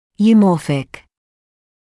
[juː’mɔːfɪk][юː’моːфик]эвморфный, соответствующий нормальному развитию (в т.ч. о сверхкомплектных зубах)